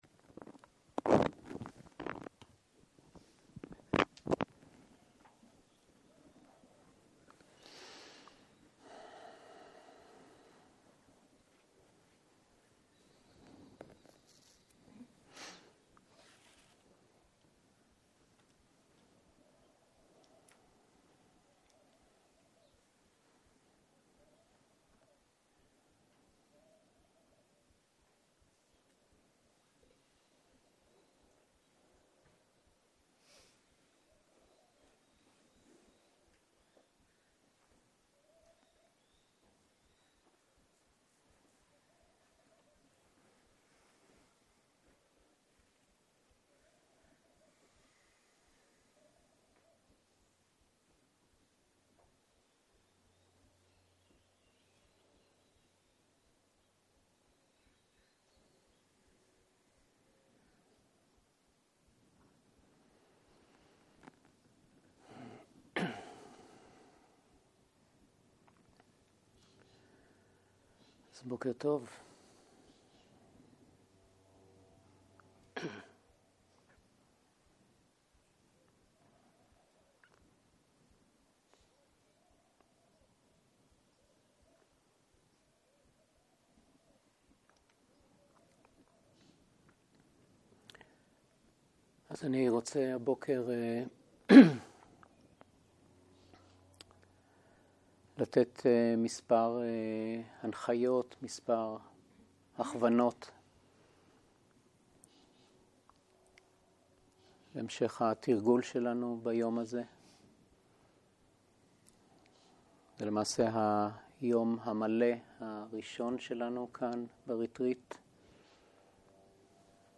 04.03.2023 - יום 2 - בוקר - הנחיות מדיטציה - תחושות הנשימה - הקלטה 2